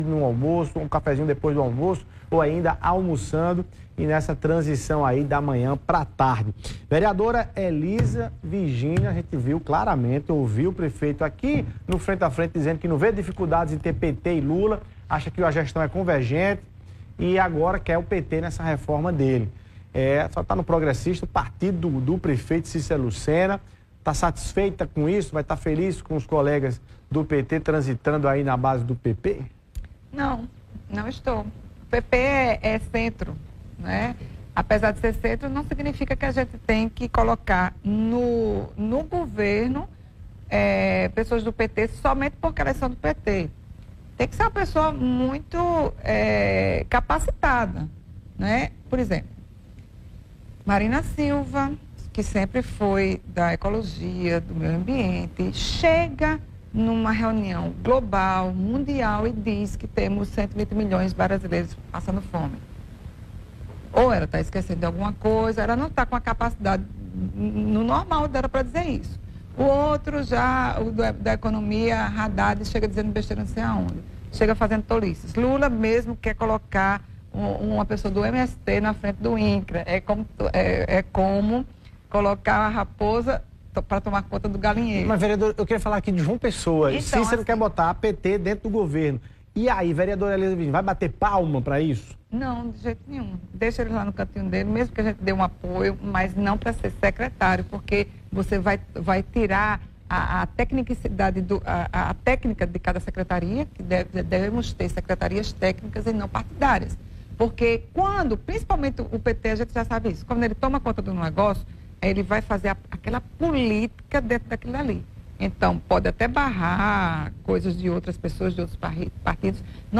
A vereadora Eliza Virgínia (Progressistas) disse em entrevista nessa quinta-feira (09) que não irá se opor a aliança de Cícero com o PT, porém faz um alerta ao gestor que o partido do presidente Lula não pode ocupar secretarias pois não tem nomes técnicos para ocupar as pastas.
Eliza-Virginia-mp3cut.net_.mp3